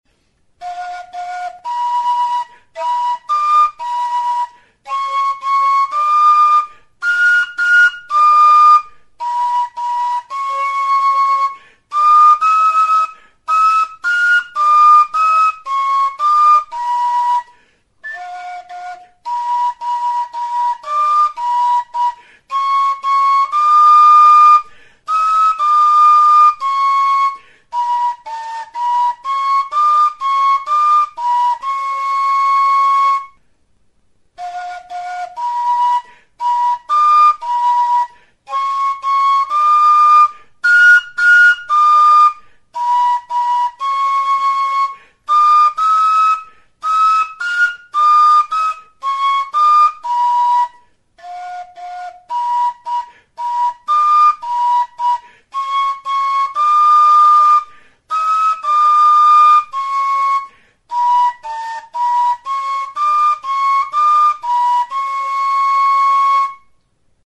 Recorded with this music instrument.
SILBOTEA; TXISTU HANDIA
Aerophones -> Flutes -> Fipple flutes (one-handed)
Hiru zuloko flauta zuzena da. Bi zatitan egindako silbote arrunta da. Si naturalean afinaturik dago. Zurezkoa da eta beltzez margotua dago.